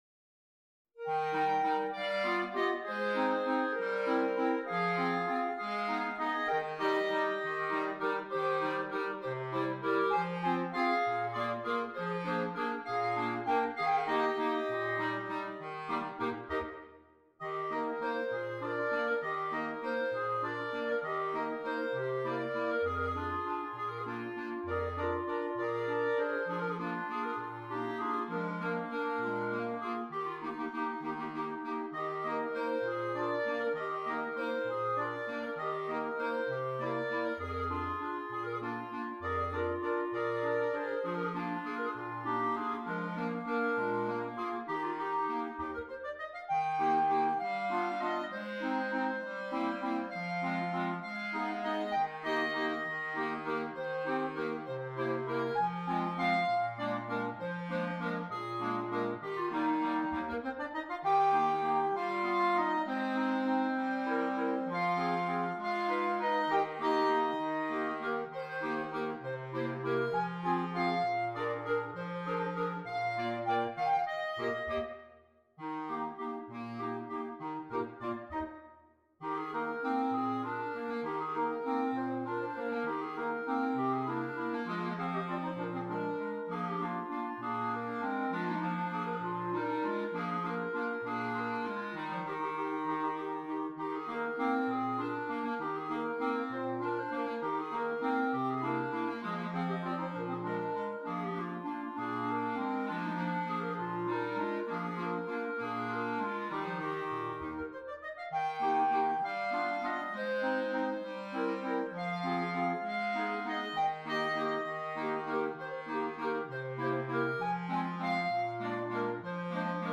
4 Clarinets, Bass Clarinet
Traditional Mexican Folk Song